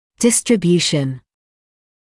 [ˌdɪstrɪ’bjuːʃn][ˌдистри’бйуːшт]распределение